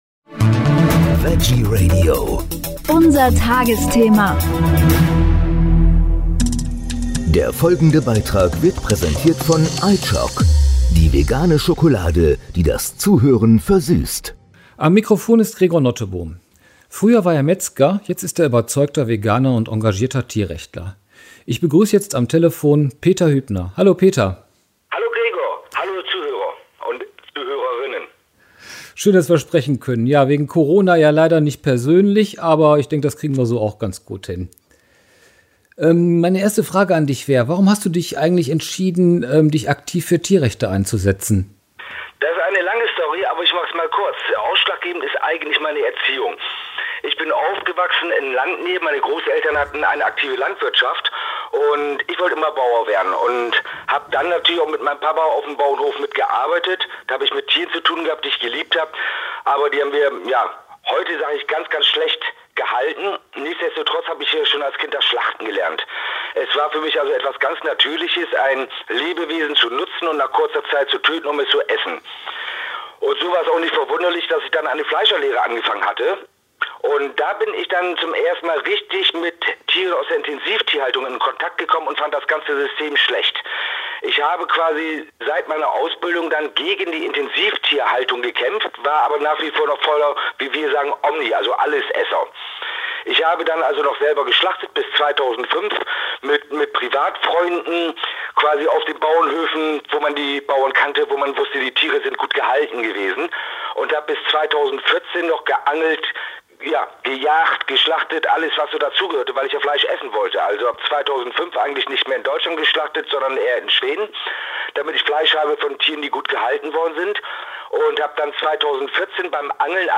Tagesthema > Gespräch